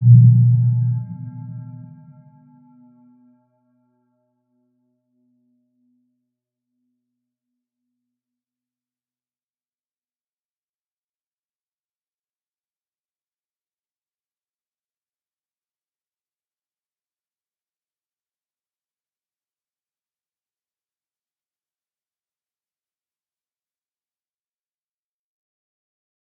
Round-Bell-B2-p.wav